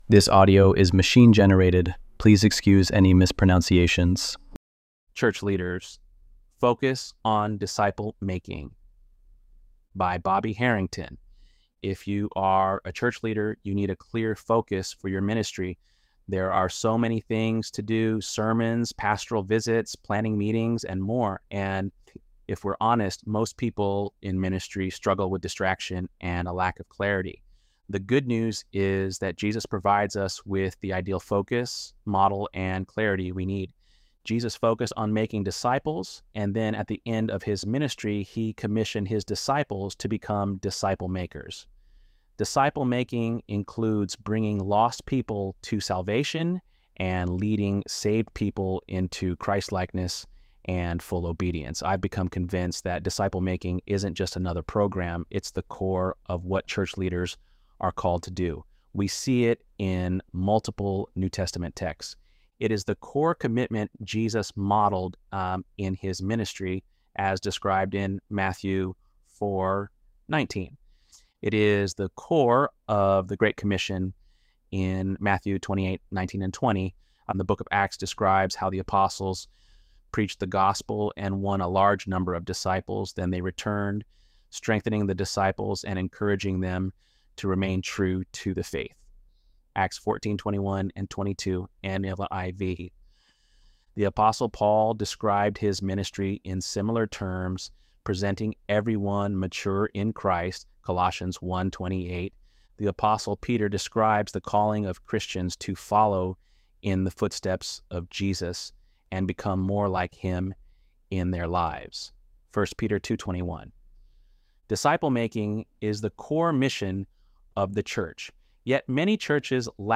ElevenLabs_2_16.mp3